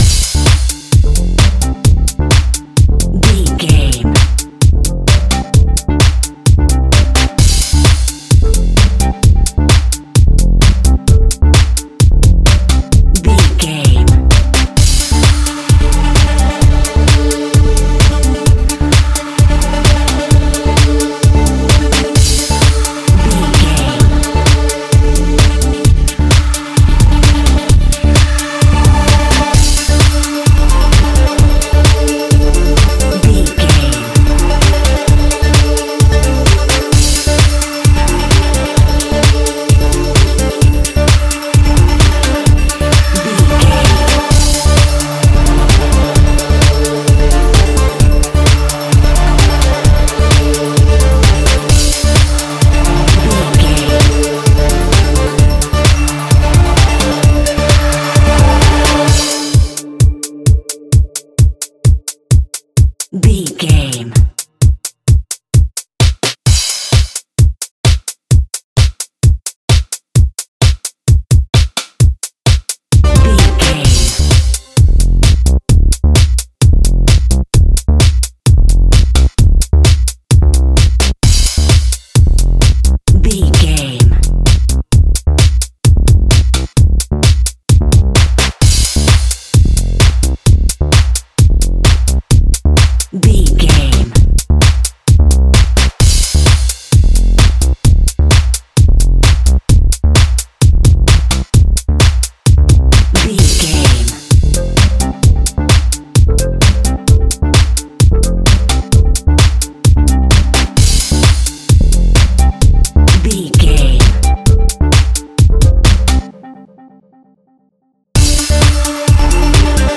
Groovy Funky Club House.
Aeolian/Minor
D
uplifting
driving
energetic
synthesiser
drum machine
electro house
synth pop
funky house